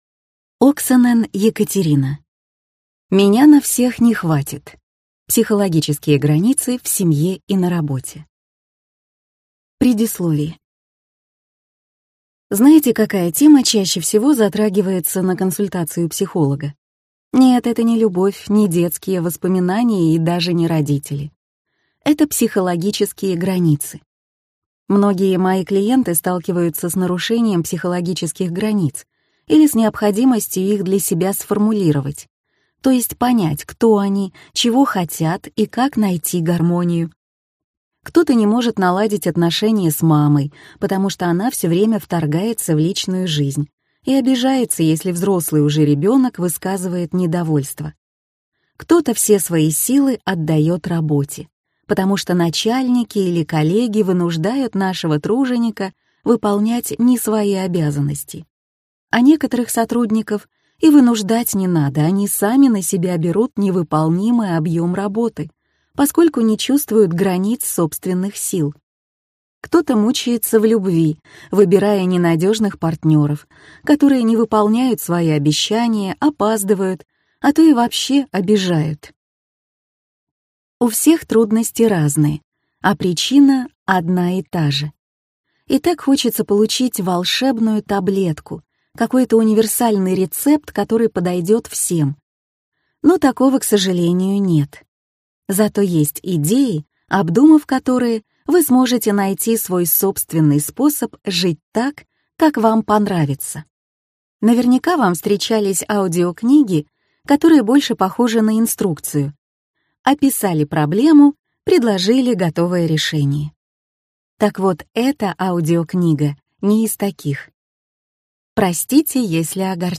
Аудиокнига Меня на всех не хватит! Психологические границы в семье и на работе | Библиотека аудиокниг